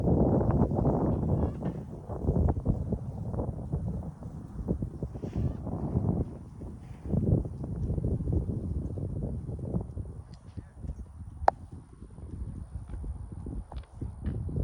I hear kids playing and people are laughing, joking and having a conversation. I hear loud Spanish music coming from Mexico having a wonderful day with their families as well in the U.S. A family was fishing and people were on a boat just having a great time.